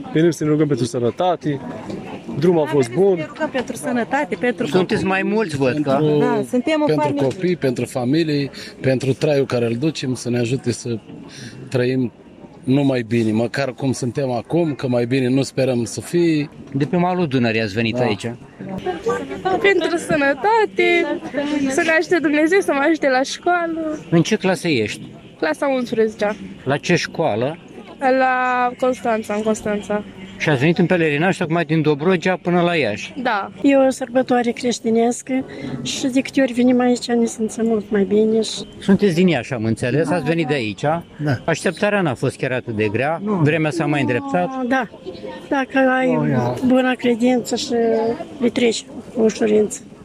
a stat de vorbă cu câțiva pelerini veniți la Iași:
11-oct-ora-12-Vox-pelerini.mp3